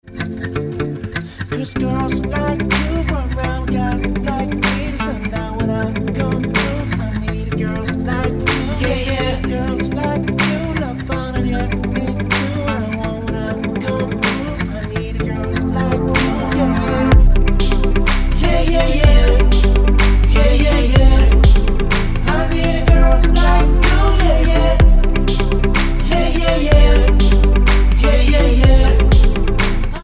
--> MP3 Demo abspielen...
Tonart:C Ohne Rap mit Chor